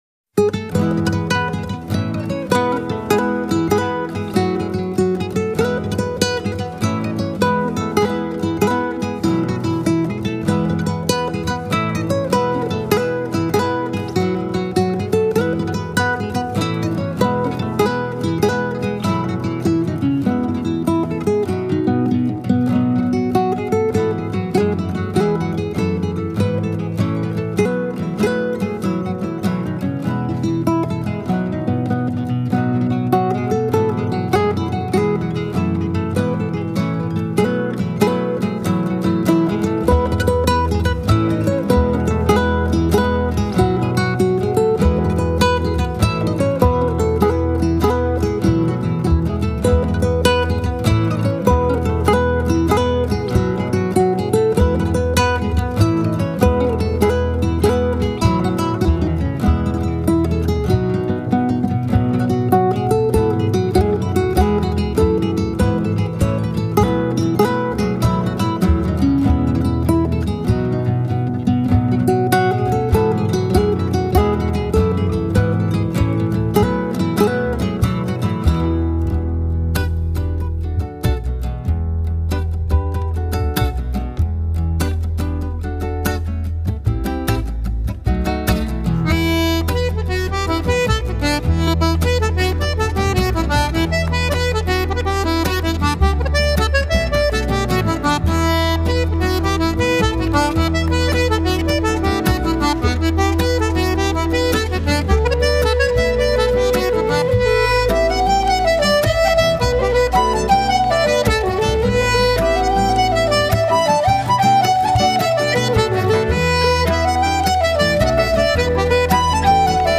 whelans_jig.mp3